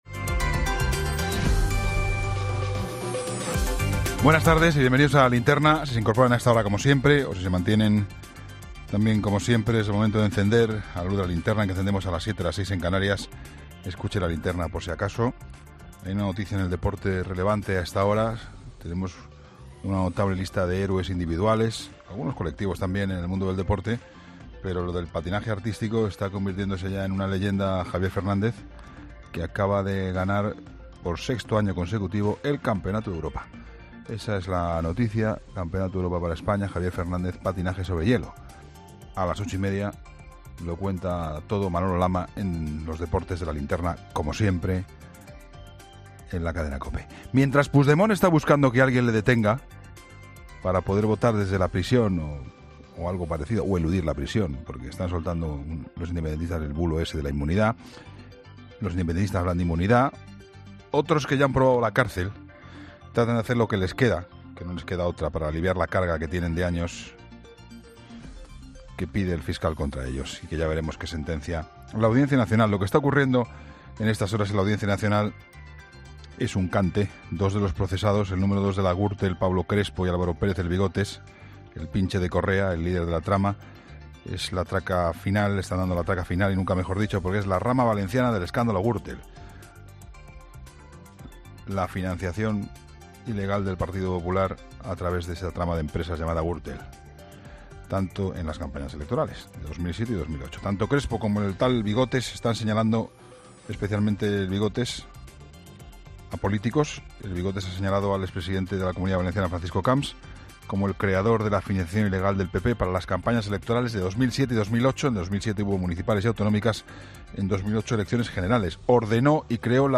El análisis de actualidad de Juan Pablo Colmenarejo a las 20h de este viernes 19 de enero, en 'La Linterna'